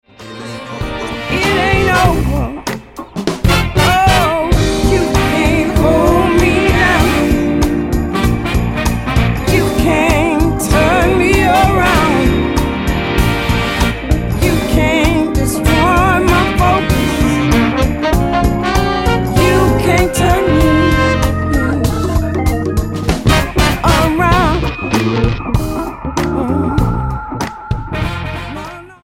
STYLE: R&B